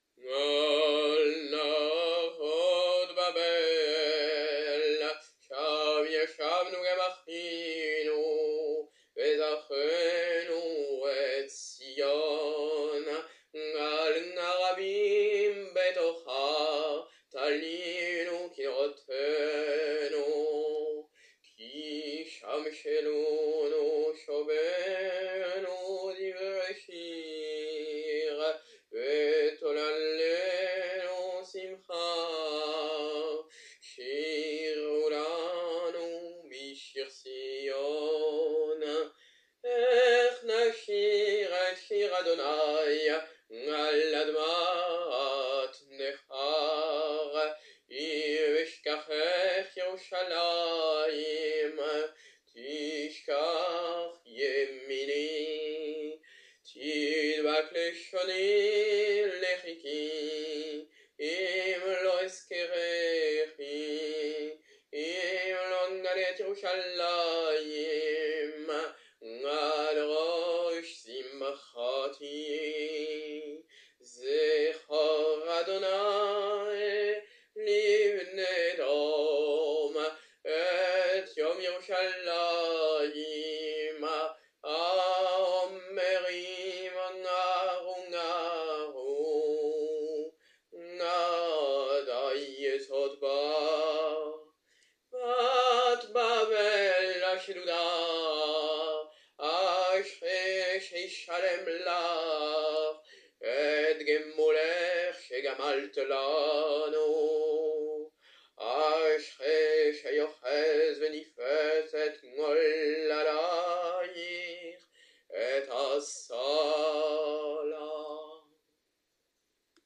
Office du soir - veille du 9 Ab :
Al Naaroth Babel - על נהרות בבל (psaume 137, se dit avant Arbit)